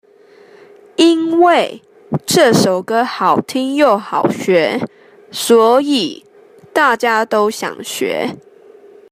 (請先仔細聆聽老師唸，再來，請你試著唸唸看。)